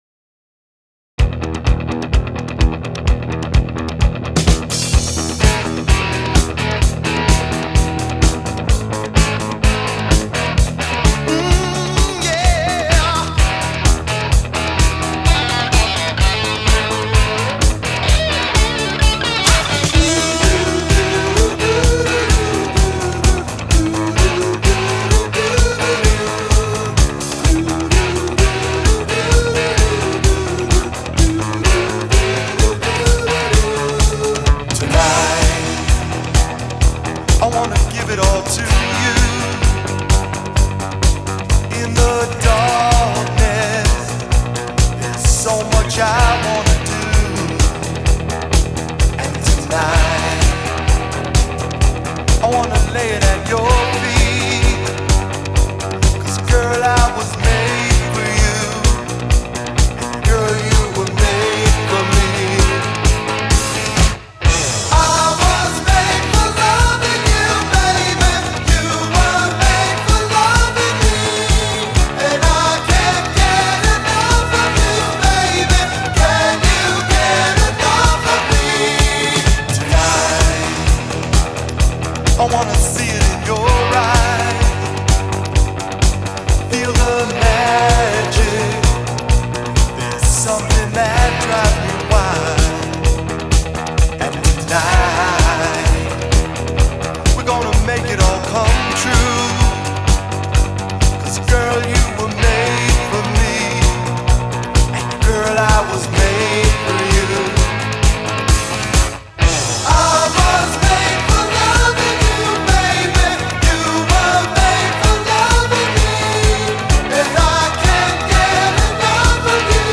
2024-08-22 22:51:55 Gênero: Rock Views